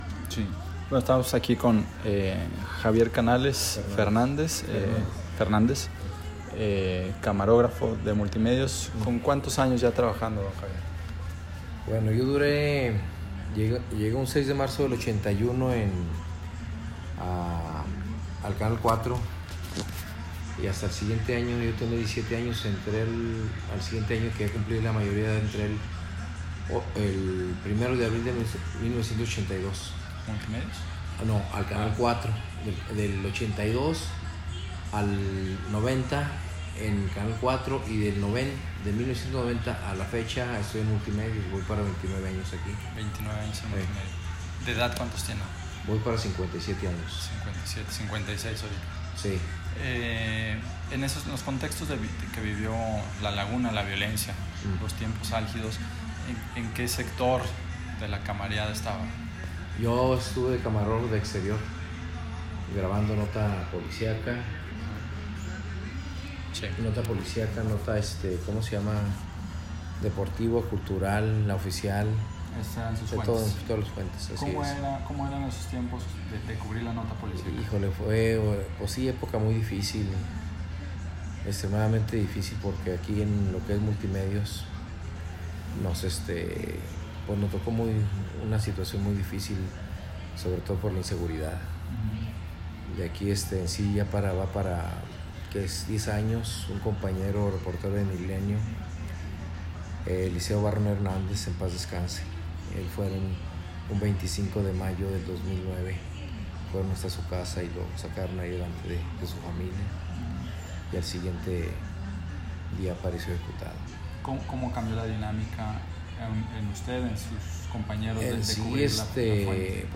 Audio de entrevista